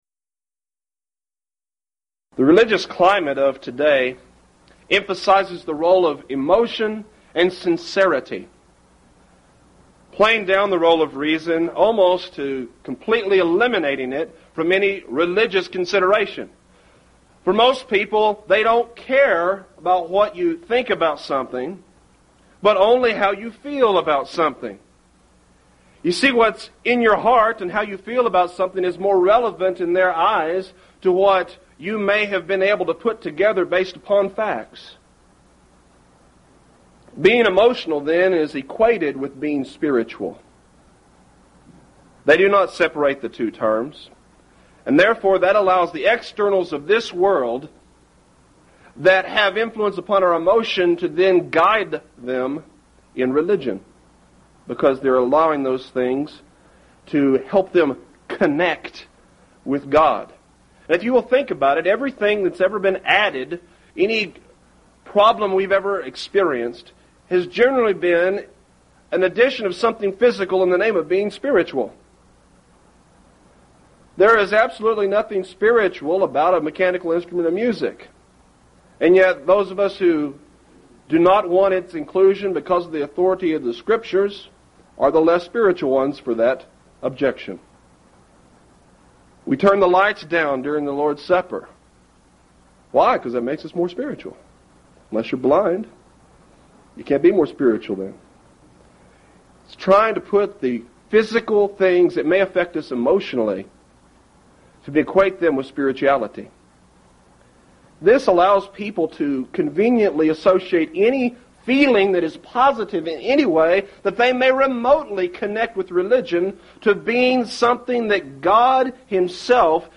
Event: 1st Annual Lubbock Lectures